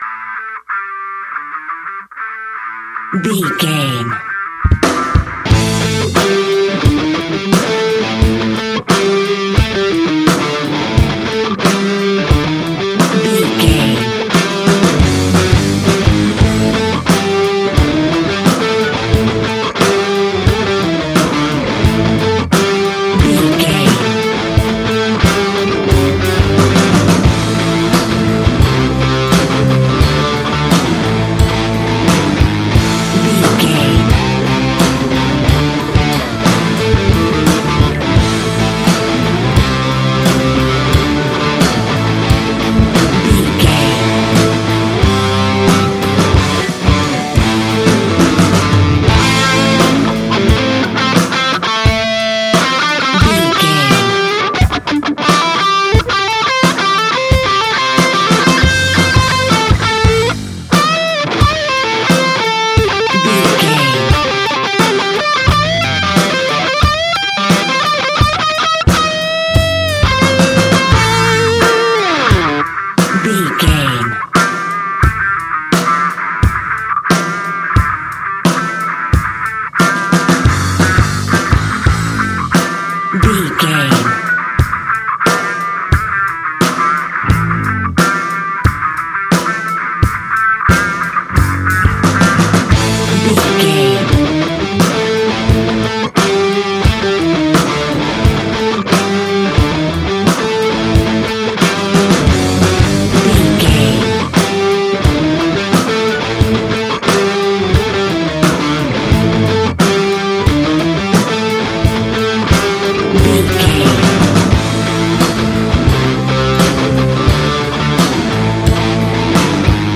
Ionian/Major
A♭
hard rock
blues rock
distortion
instrumentals